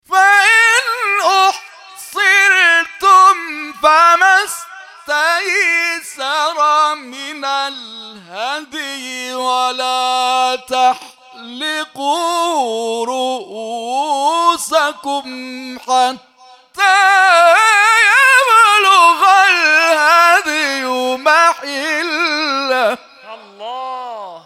گروه فعالیت‌های قرآنی: فرازهایی شنیدنی از قاریان ممتاز کشور را می‌شنوید.
مقطعی از تلاوت